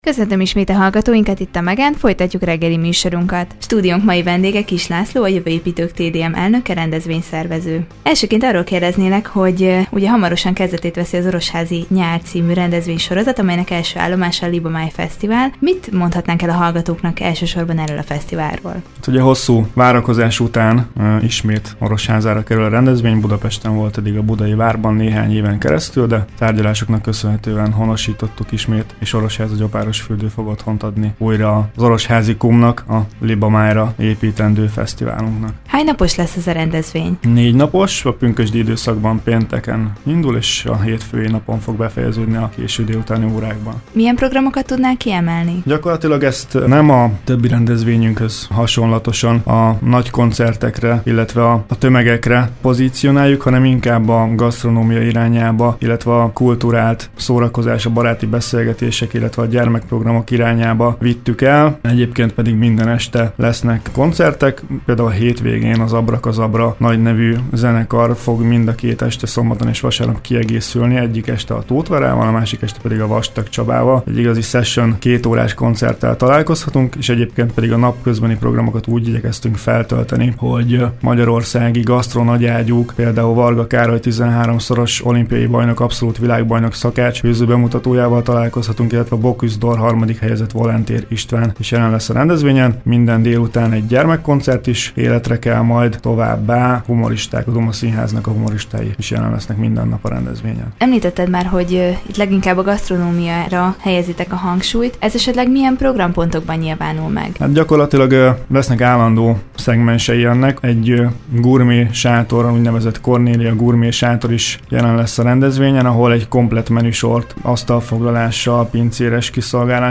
Vele beszélgetett tudósítónk a közelgő Orosházi Nyár rendezvénysorozatról.